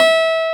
CLAV A4.wav